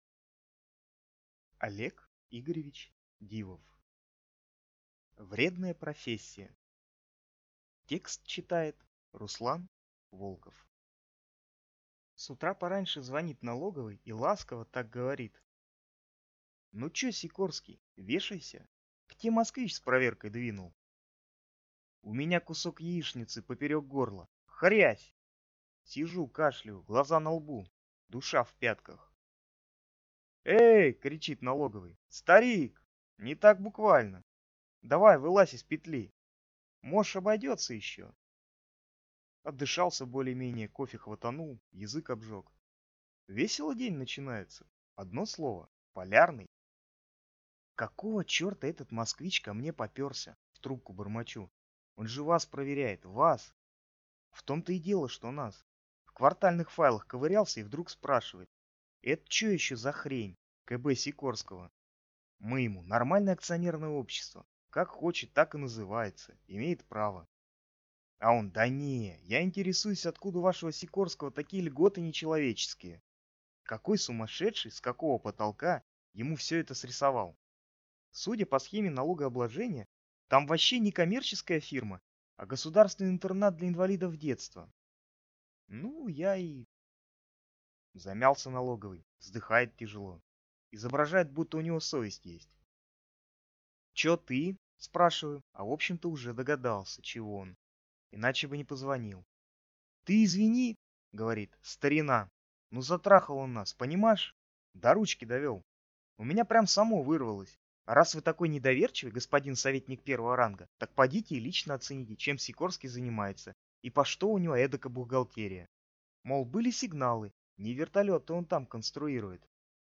Аудиокнига Вредная профессия | Библиотека аудиокниг